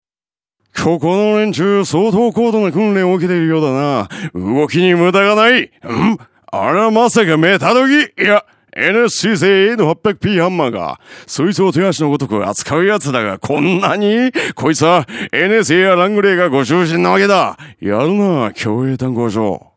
ラジオCM制作
有名アニメキャラクターを彷彿させる語り口で、壮大な世界観をラジオCMで表現。